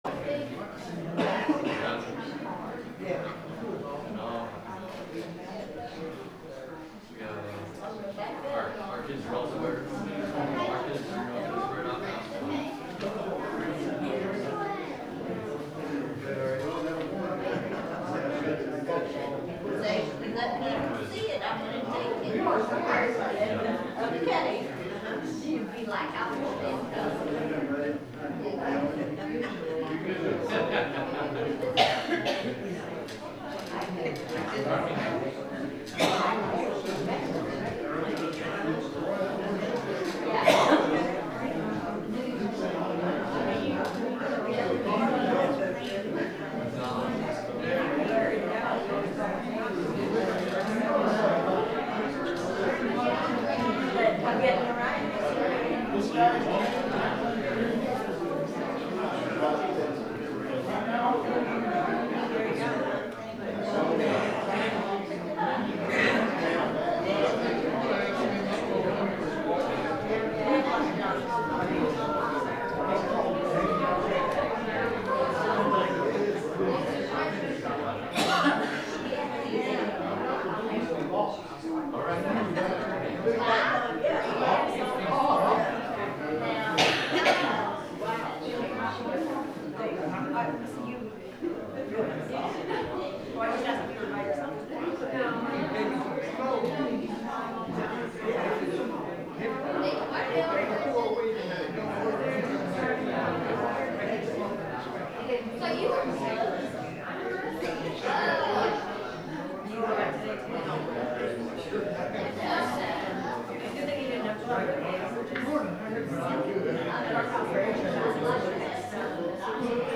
The sermon is from our live stream on 11/30/2025